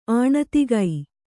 ♪ āṇatigai